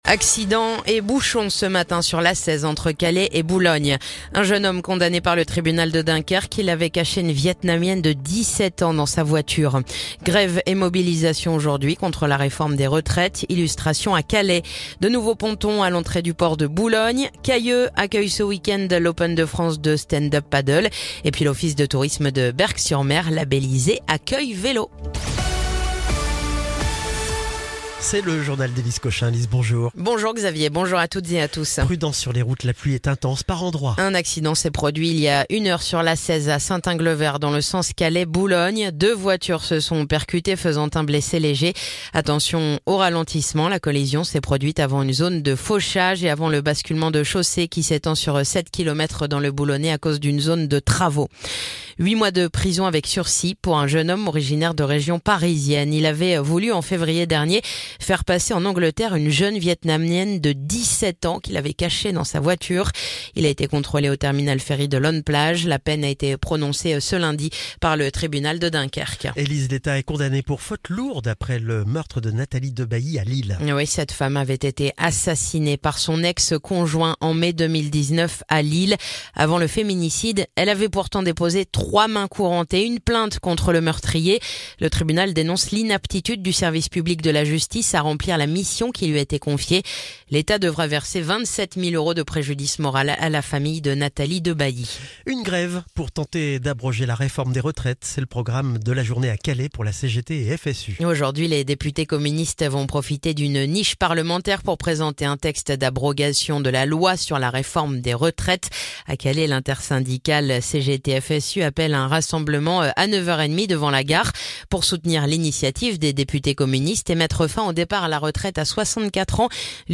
Le journal du jeudi 5 juin